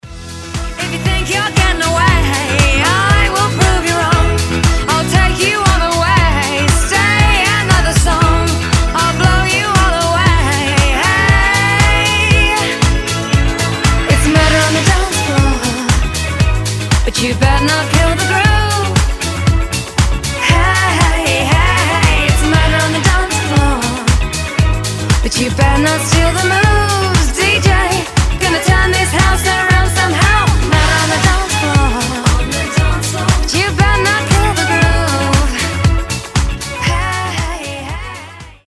танцевальные
диско , заводные